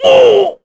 Kaulah-Vox_Damage_kr_02.wav